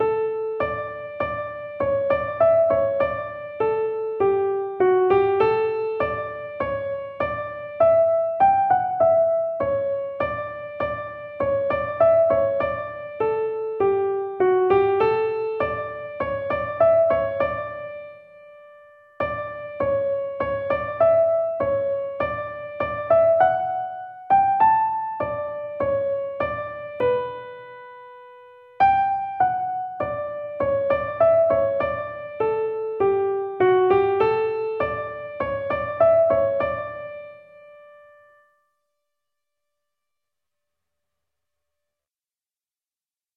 Each tune is available as a PDF (sheet music) and MP3 (audio recording played slowly for learning).